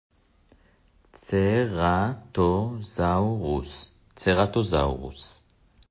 צר-טו-זאו-רוס